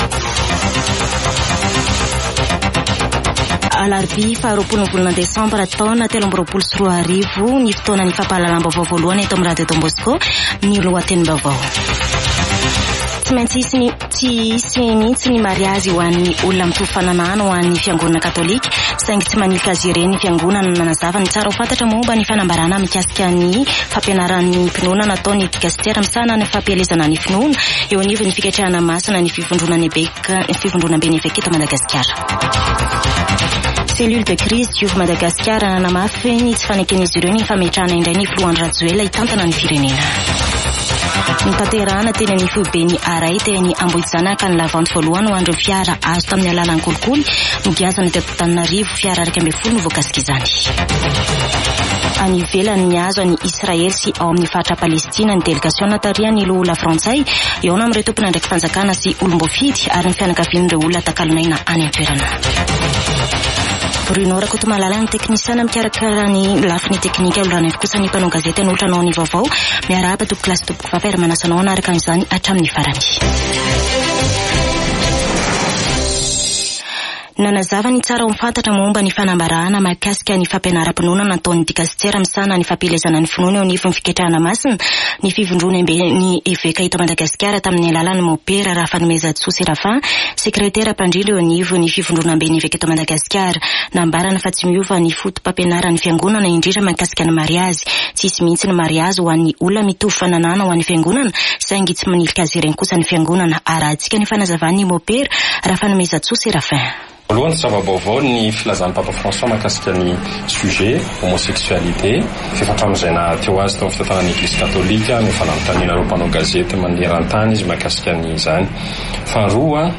[Vaovao maraina] Alarobia 20 desambra 2023